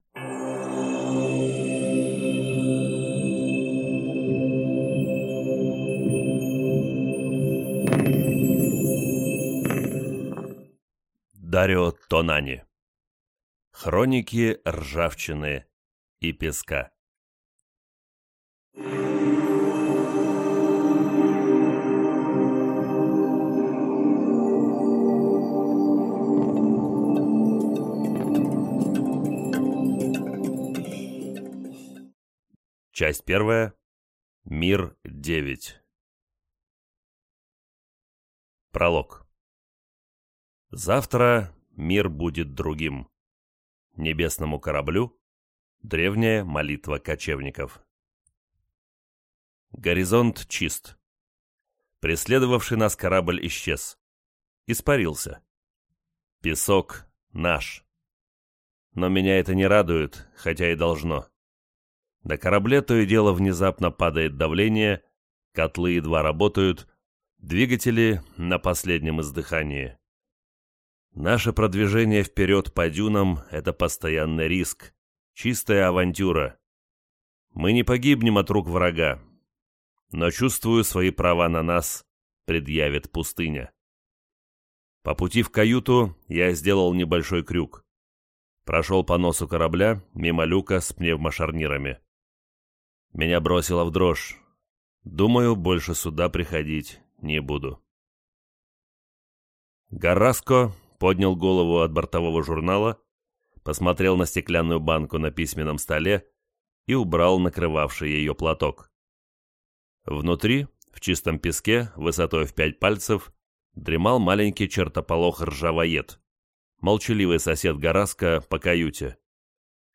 Аудиокнига Хроники ржавчины и песка | Библиотека аудиокниг